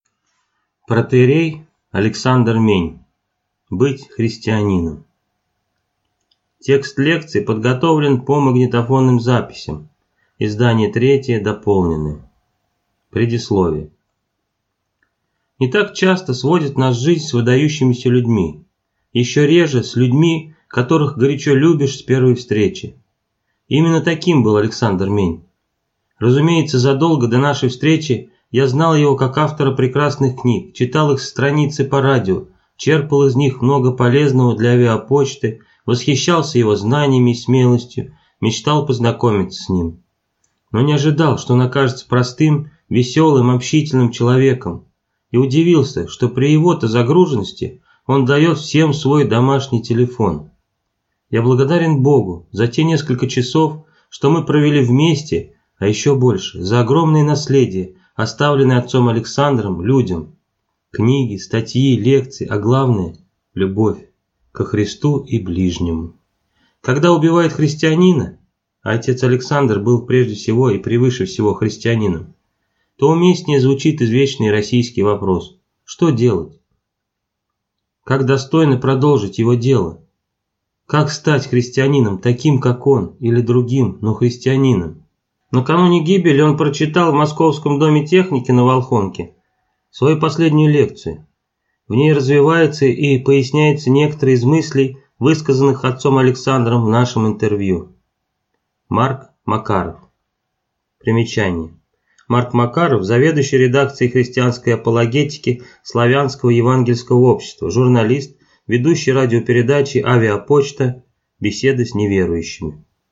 Аудиокнига Быть христианином | Библиотека аудиокниг